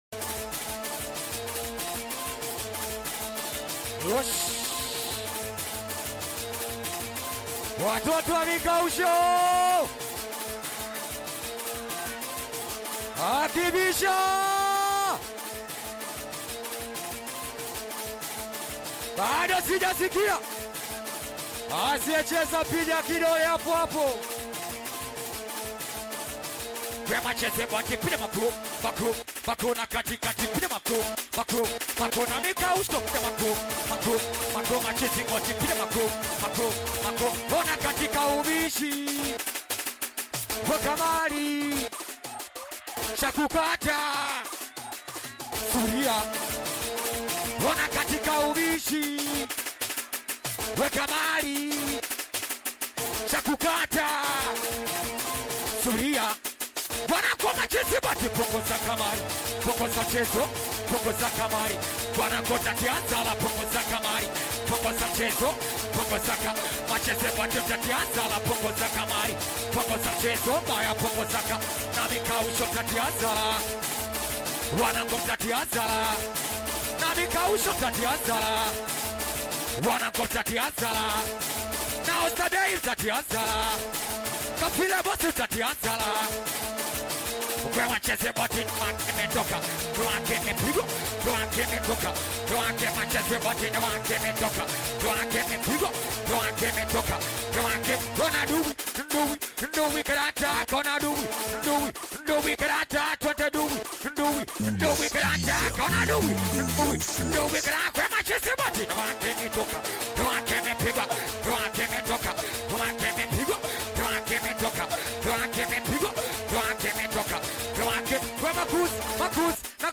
high-energy Tanzanian Hip-Hop/Live performance track
Singeli